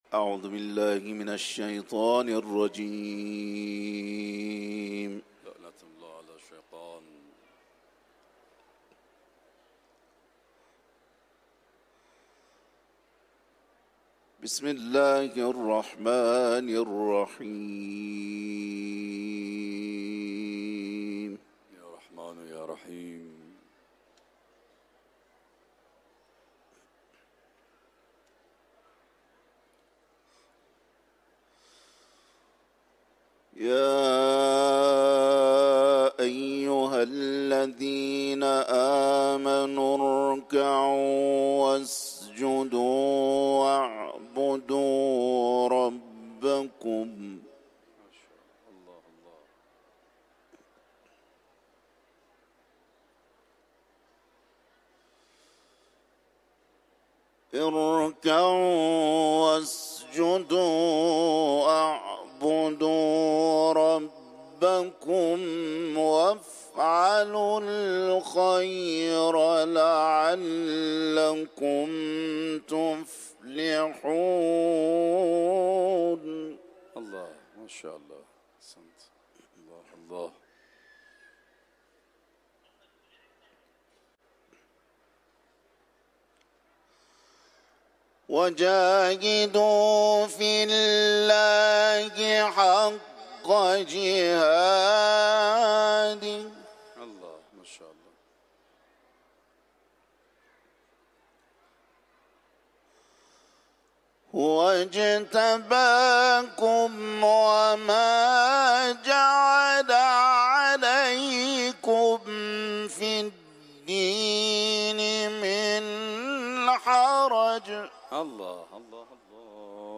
تلاوت قرآن ، سوره حج